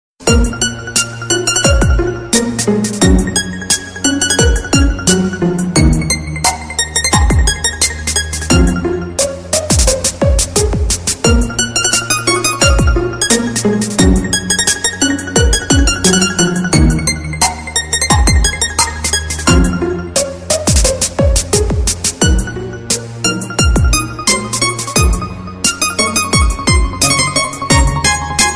• Качество: Хорошее
• Категория: Рингтоны